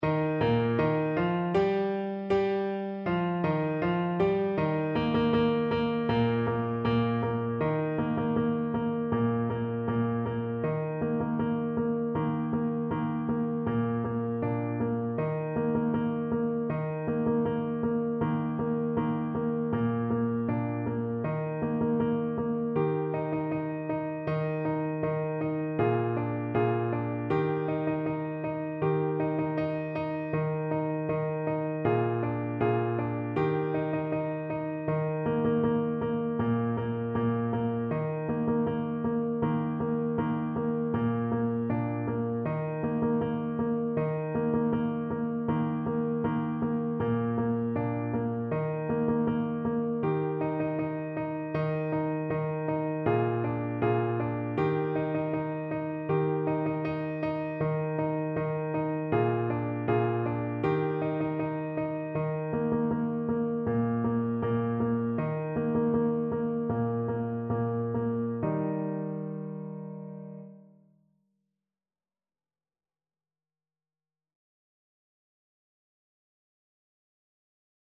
Alto Saxophone version
World Africa Tunisia
Alto Saxophone
4/4 (View more 4/4 Music)
Two in a bar =c.120
Traditional (View more Traditional Saxophone Music)